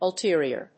ul・te・ri・or /ʌltí(ə)riɚ‐riə/
• / ʌltí(ə)riɚ(米国英語)
• / ʌltí(ə)riə(英国英語)